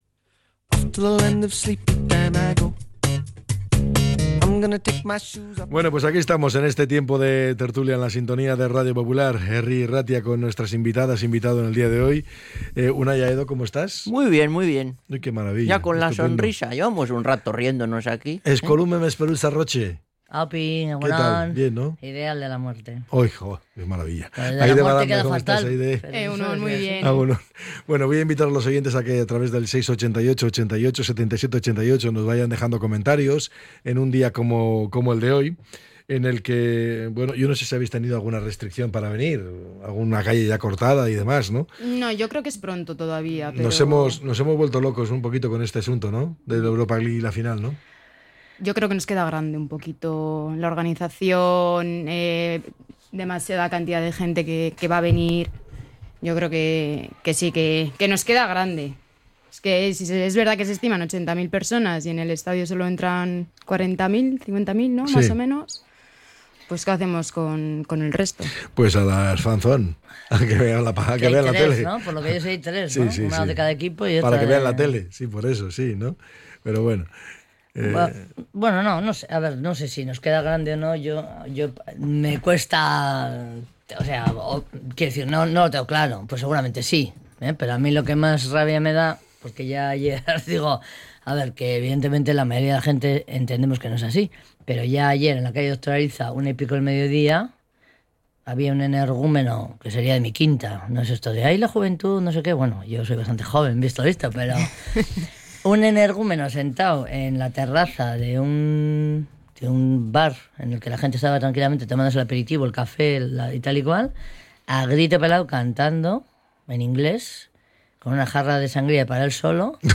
La tertulia 20-05-25.